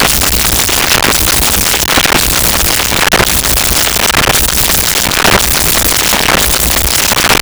Machine Sonic Pulse
Machine Sonic Pulse.wav